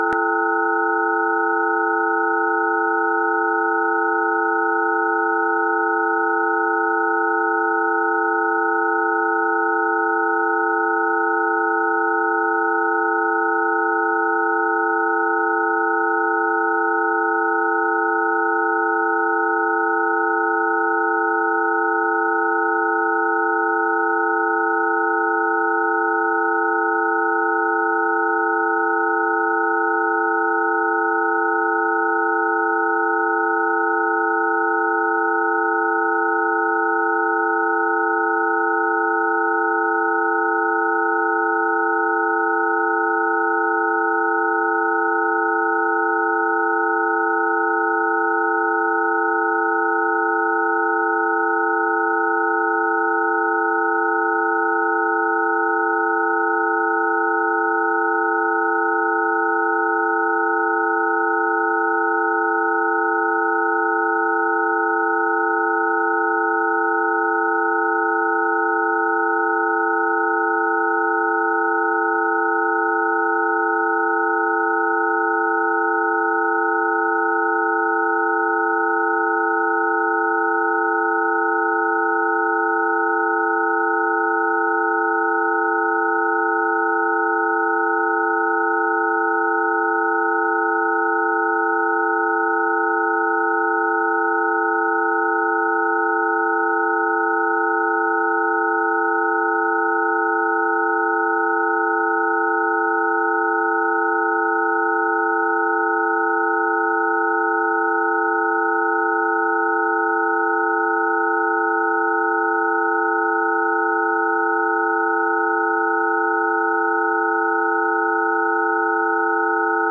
基于我发现的研究 我做了一些超声处理（将数据缩放到可听范围）以“可视化”听起来如何。 注意：这是测量数据的超声处理，而不是实际的顺势疗法补救措施。